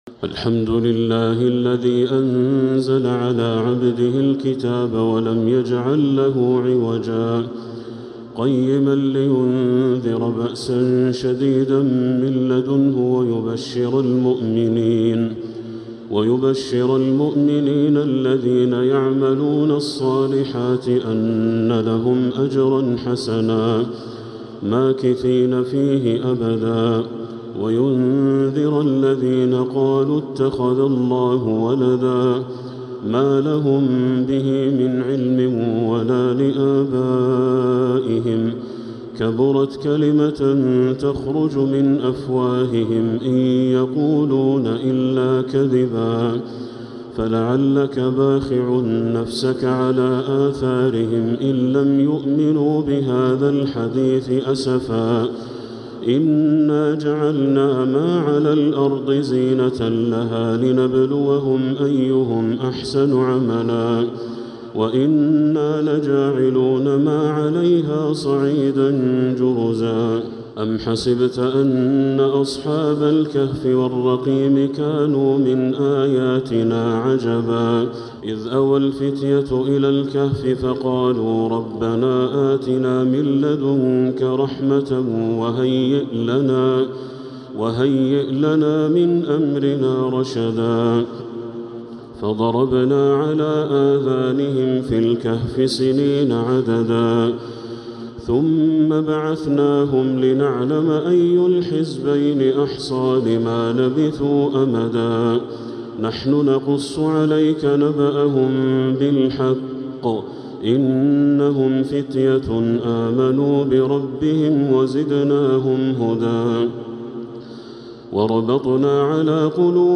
من المسجد الحرام | Surat Al-kahf